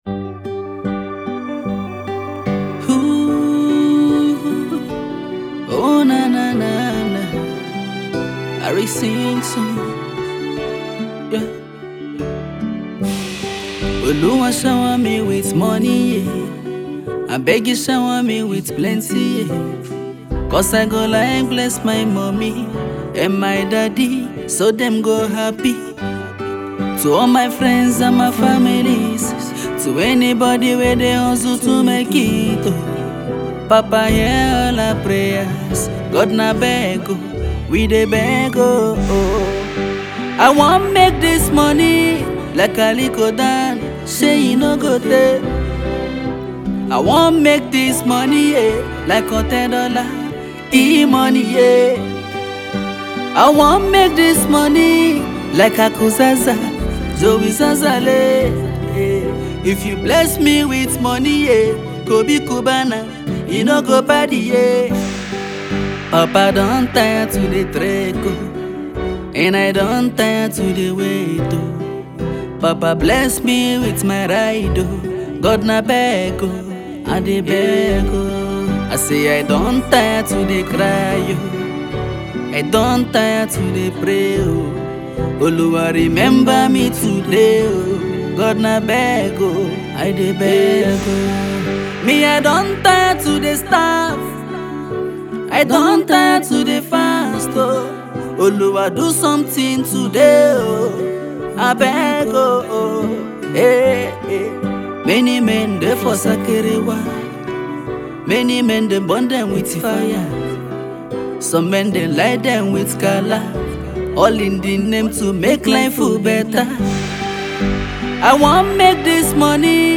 by fast-emerging Afropop singer and songwriter
hustler’s anthem